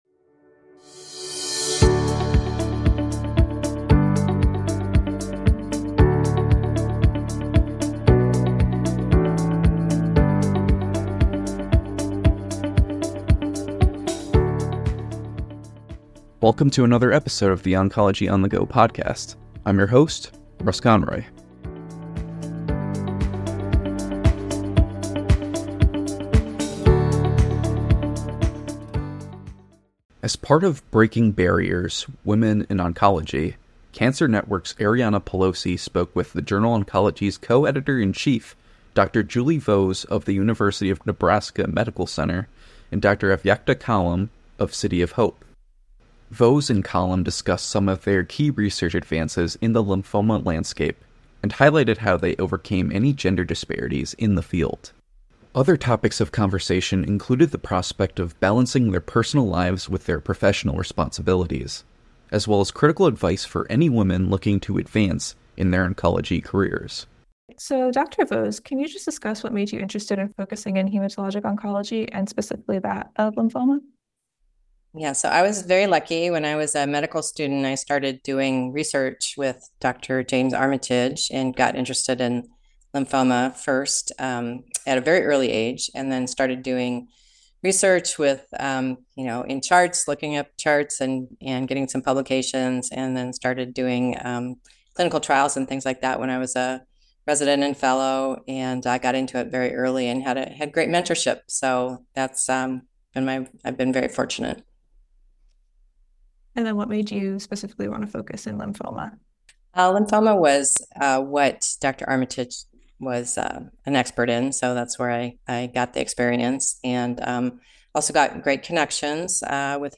The conversation highlighted how this evolution of therapy options has helped improve patient outcomes while reducing hospitalization periods and minimizing toxicity.